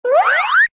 toonbldg_grow.ogg